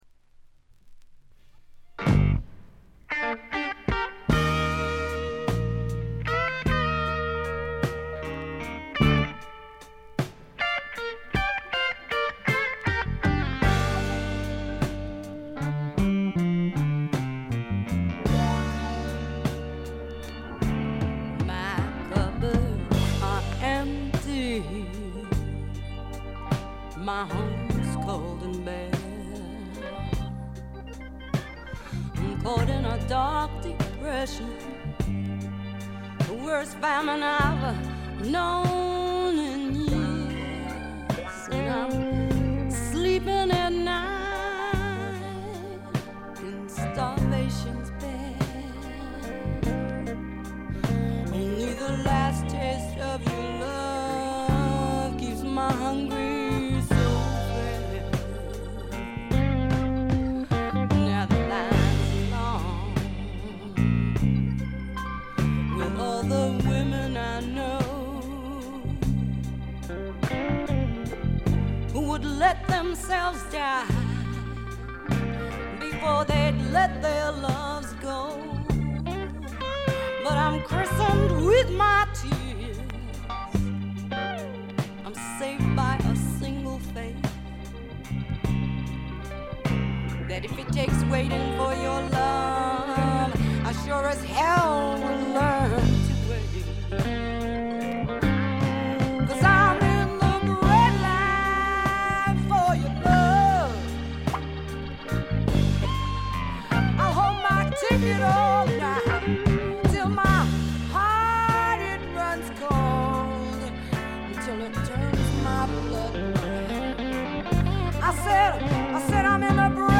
部分試聴ですが、わずかなノイズ感のみ。
ファンキーでタイト、全編でごきげんな演奏を繰り広げます。
試聴曲は現品からの取り込み音源です。
Recorded At - The Sound Factory